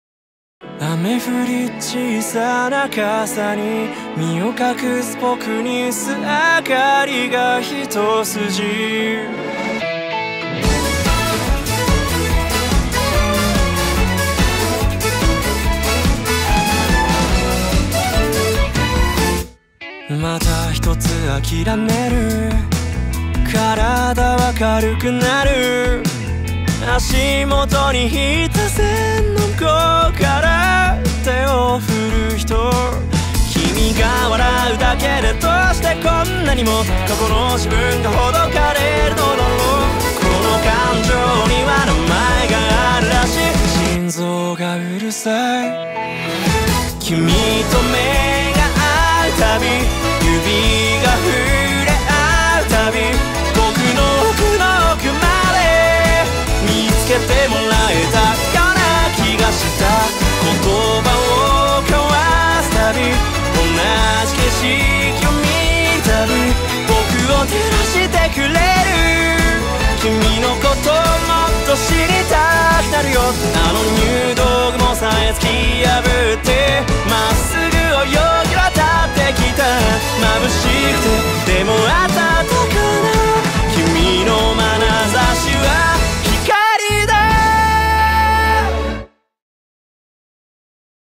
BPM144
Audio QualityPerfect (High Quality)